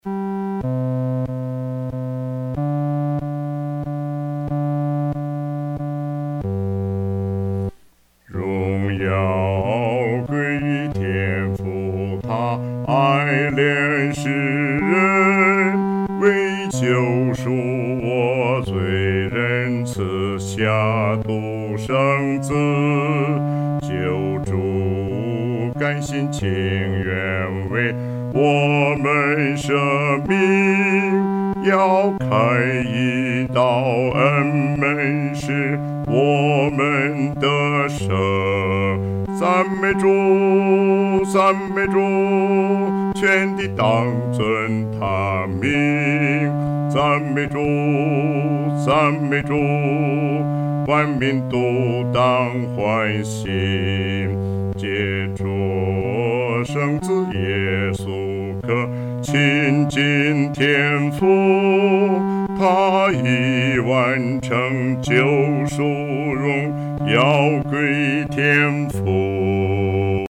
独唱（第四声）